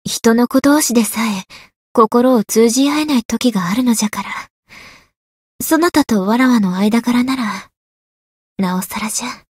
灵魂潮汐-蕖灵-问候-不开心.ogg